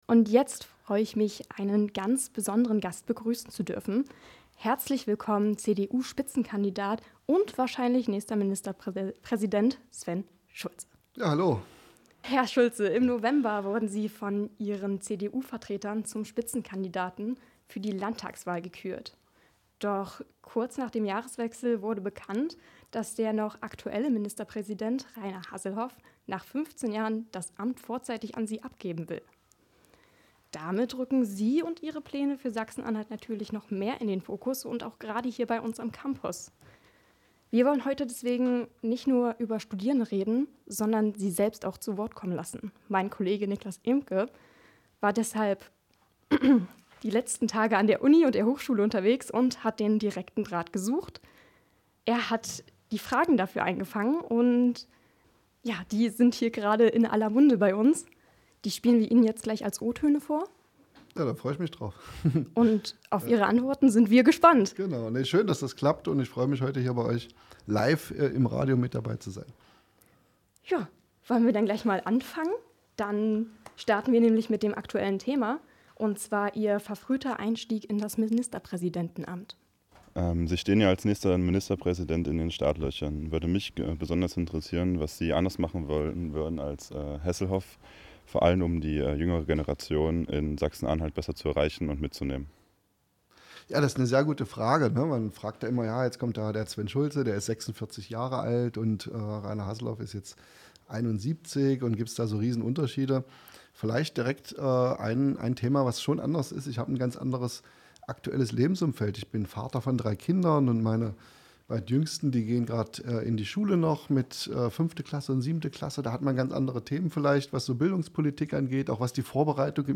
CDU-Spitzenkandidat Sven Schulze im Campusinterview
In der letzten Sendung durften wir Sven Schulze live im Studio begrüßen.